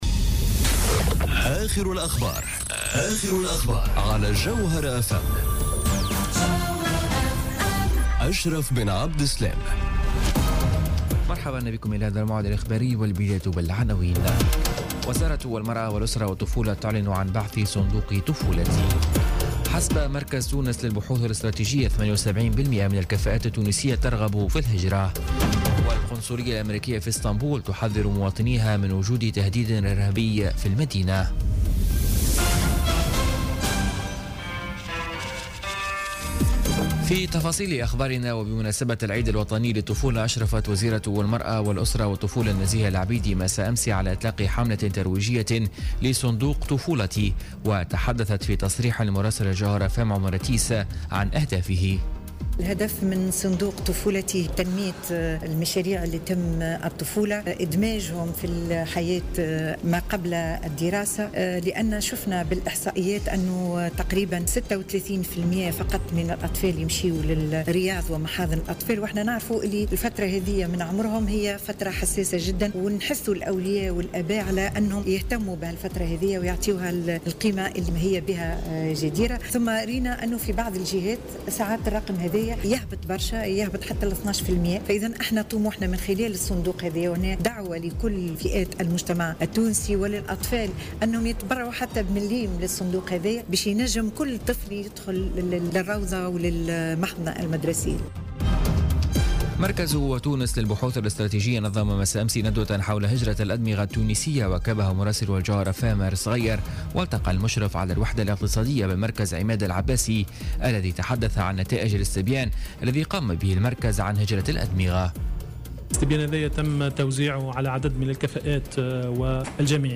نشرة أخبار منتصف الليل ليوم السبت 17 جوان 2017